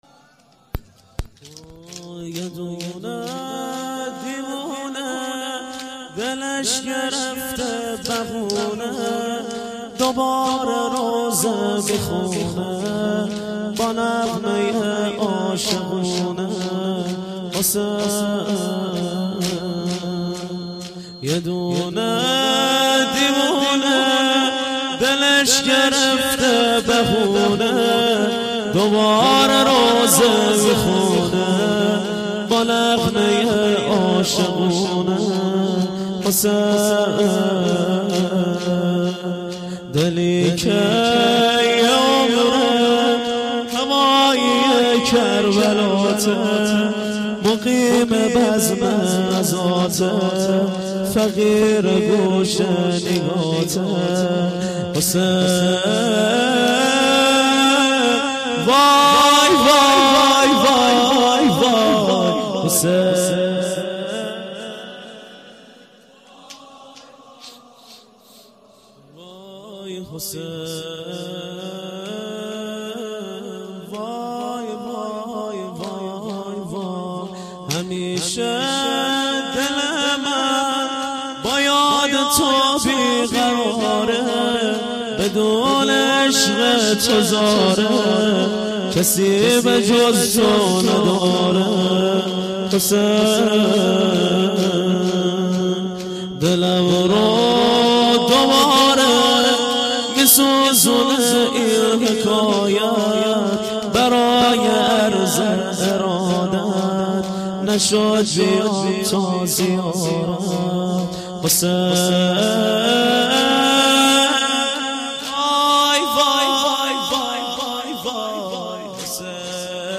چهار ضرب - یه دونه دیونه